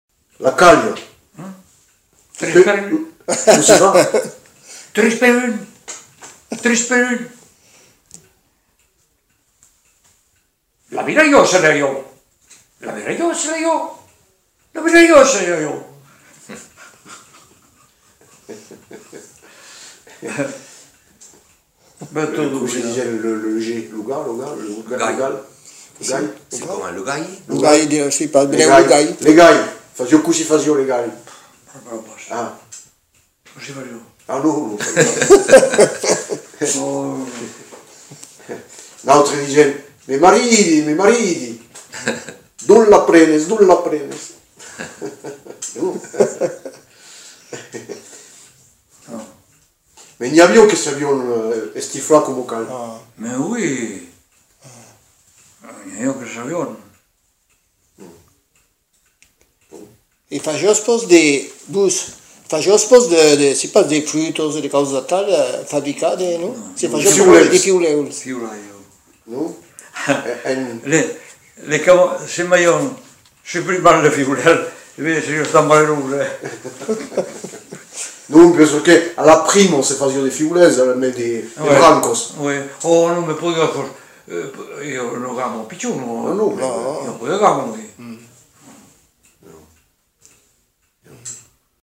Mimologismes d'oiseaux
Aire culturelle : Lauragais
Lieu : Le Faget
Genre : forme brève
Descripteurs : oiseau ; imitation d'un animal ; mimologisme
Notes consultables : Mimologismes de la caille, du geai.